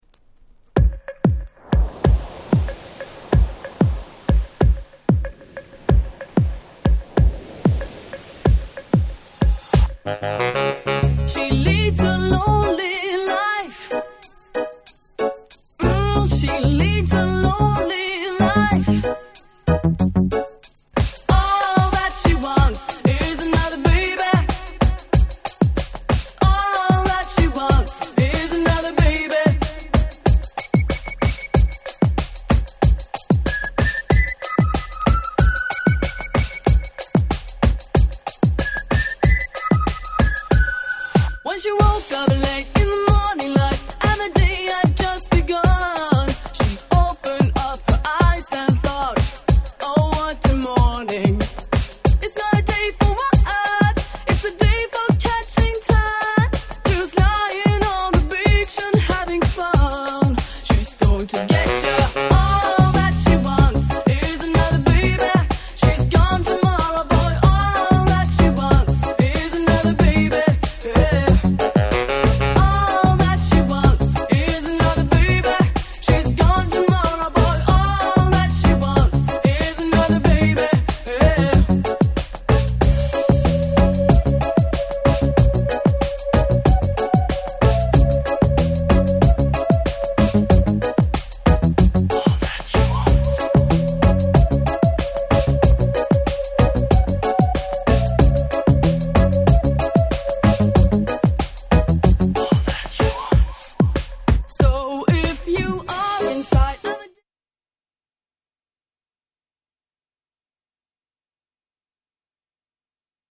この曲のヒット後、この曲のポコポコした何とも言えないBeatを模倣したDance PopやR&Bが急増！！